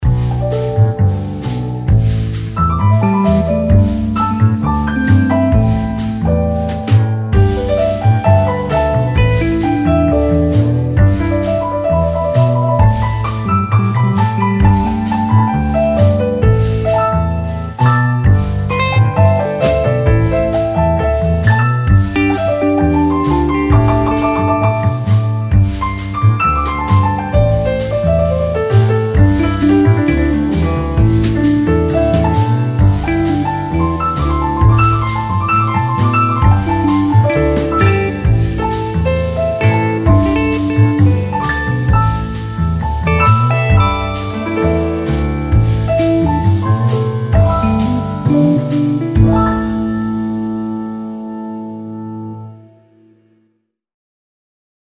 Largo [0-10] - - bar - romantique - jazzy - doux - nuit